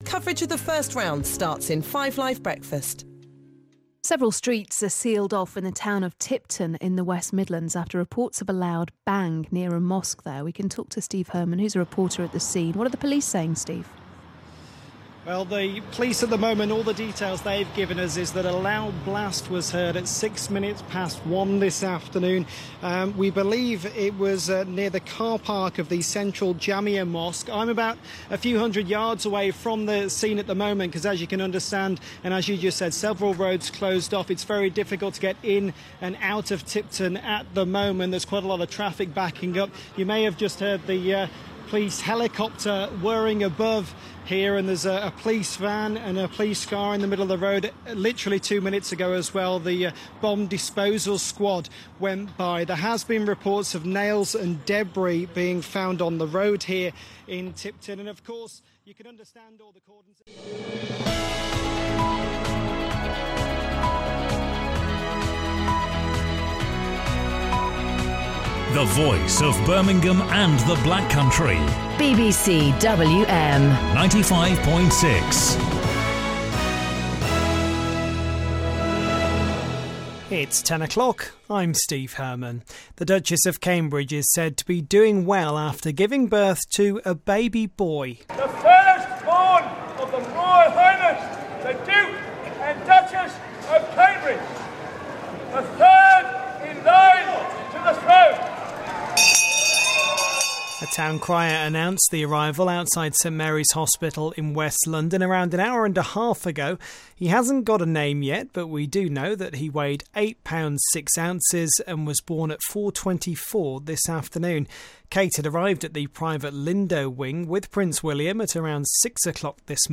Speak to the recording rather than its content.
Here's a snippet of what I've done at BBC WM so far, including live reporting from the scene of a terrorist incident on 5 Live, a news bulletin on the Royal Baby and a live 2-way on a Breaking News Story.